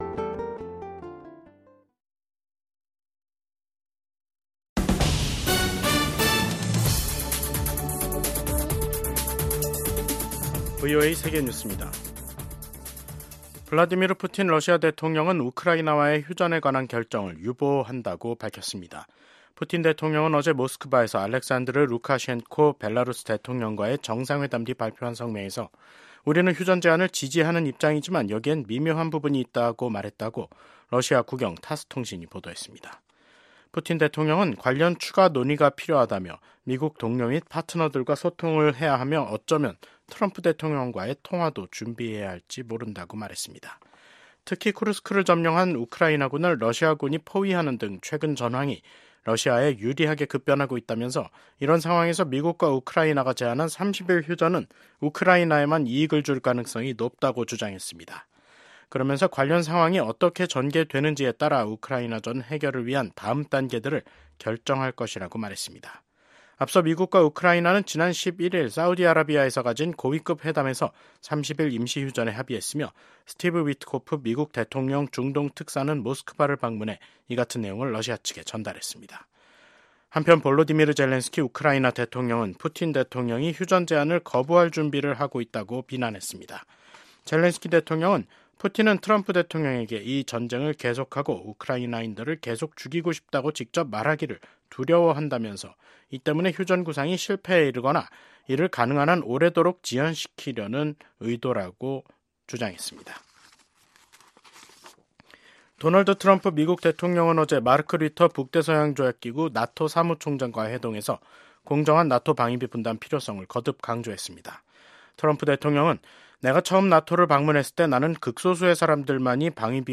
VOA 한국어 간판 뉴스 프로그램 '뉴스 투데이', 2025년 3월 14일 2부 방송입니다. 도널드 트럼프 미국 대통령이 또다시 북한을 ‘뉴클리어 파워’ 즉 ‘핵 국가’로 지칭했습니다. 일본 주재 미국 대사 지명자가 미한일 3국 협력 강화가 필수적이라며 지속적인 노력이 필요하다고 강조했습니다. 지난 10년 동안 북한 선박 8척이 중국해역 등에서 침몰한 것으로 나타났습니다.